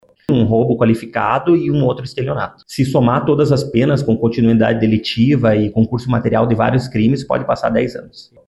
O delegado disse que o suspeito tem passagem pelo sistema prisional.